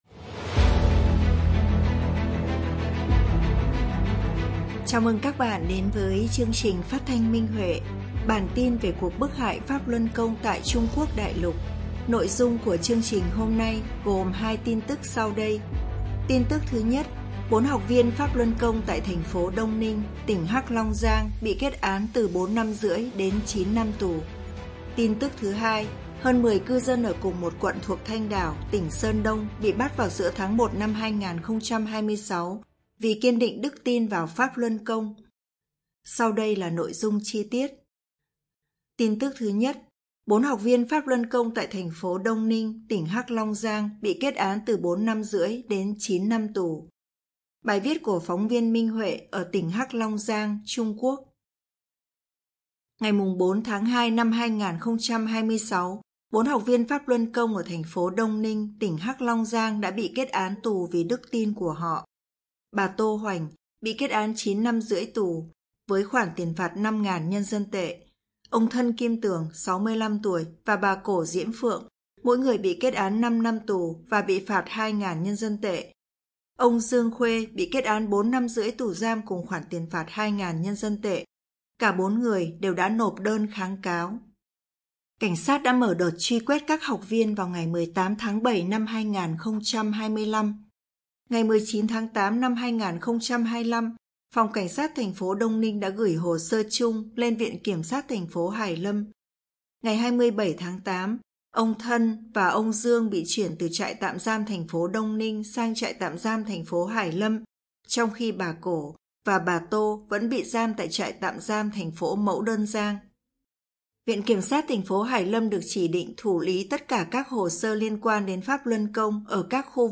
Nội dung của chương trình hôm nay gồm tin tức sau đây: